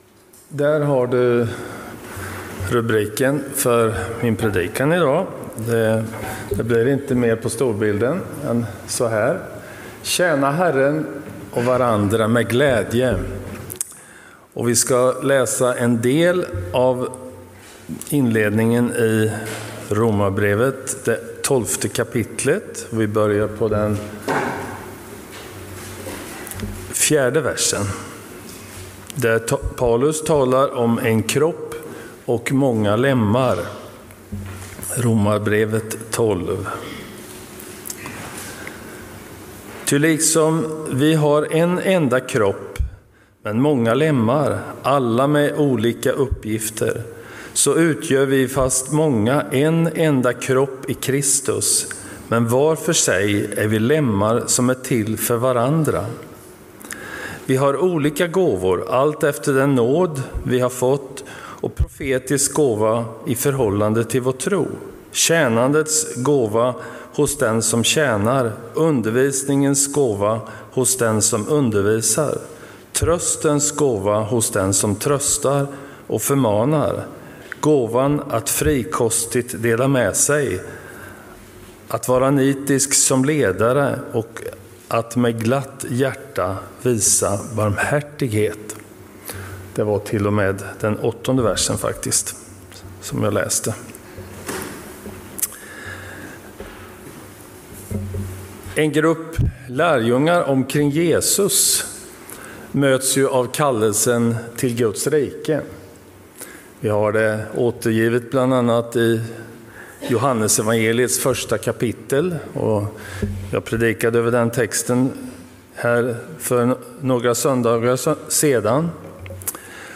Gudstjänst i Centrumkyrkan Mariannelund